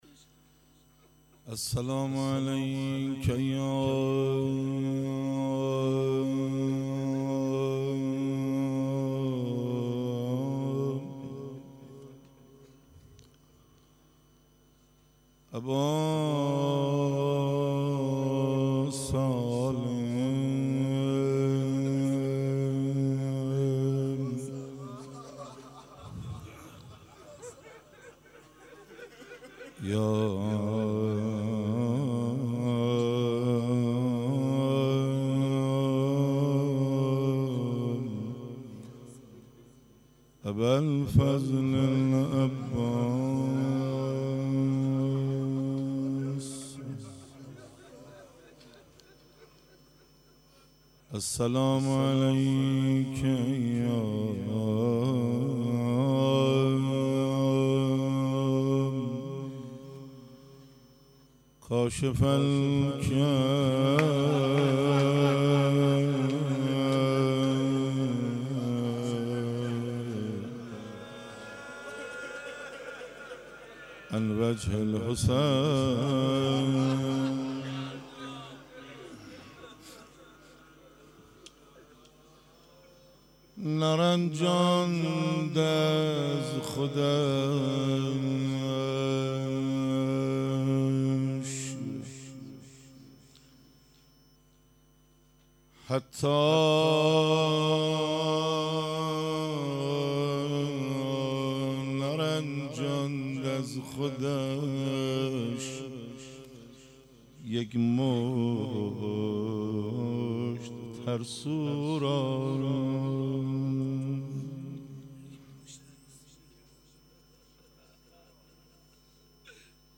26 مهر 97 - حسینیه بیت الحسین - روضه
روضه خوانی